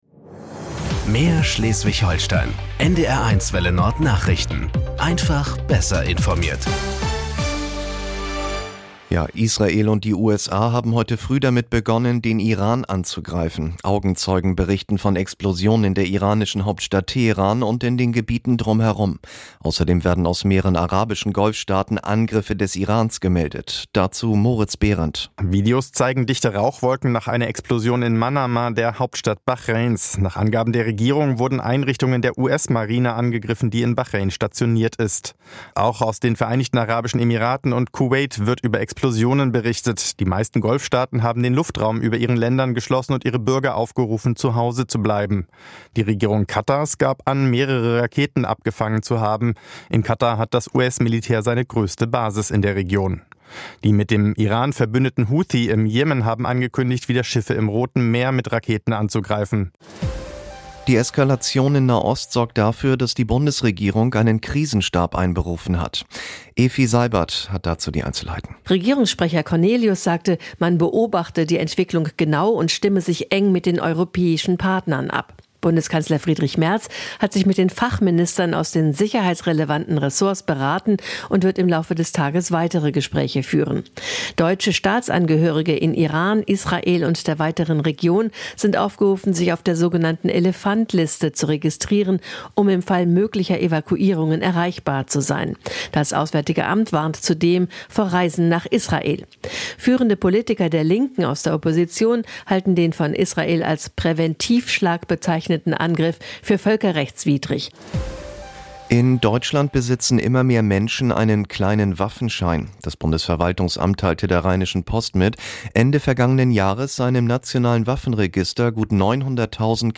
Nachrichten 12:00 Uhr - 28.02.2026 ~ NDR 1 Welle Nord – Nachrichten für Schleswig-Holstein Podcast